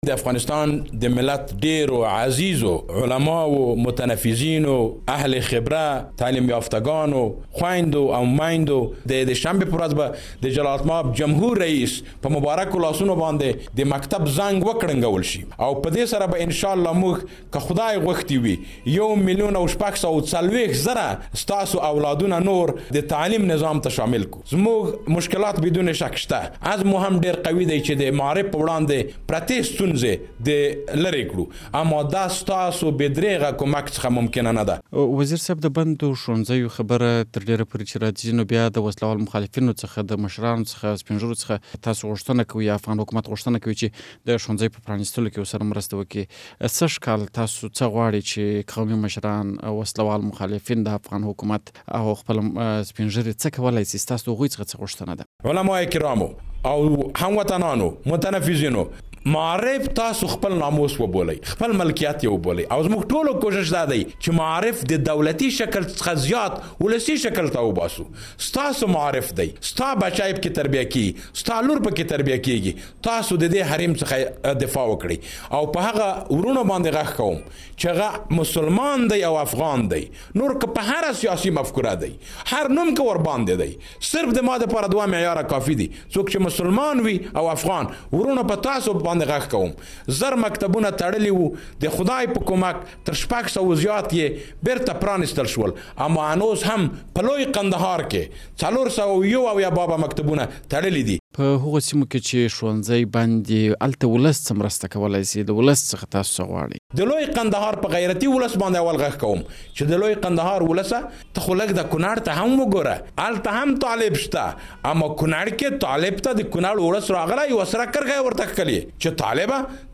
له ډاکټر فاروق وردک سره مرکه